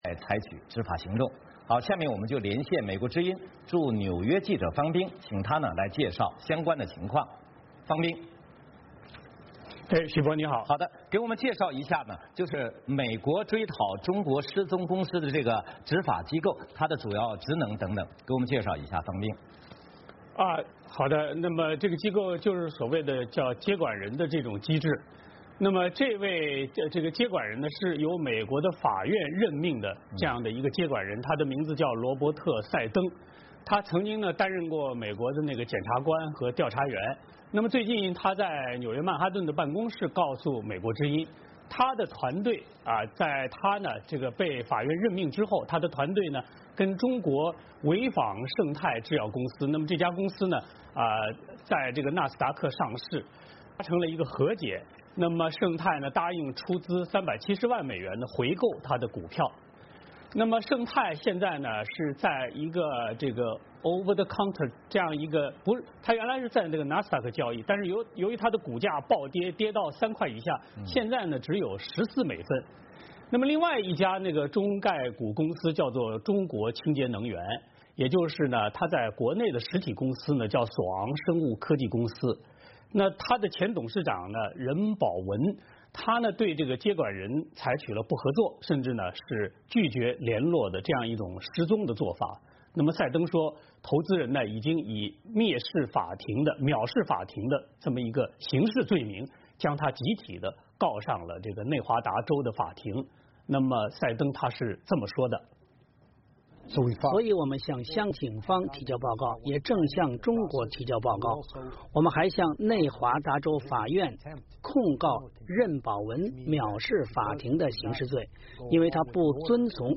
VOA连线：美国加强对中国上市公司的监管和执法力度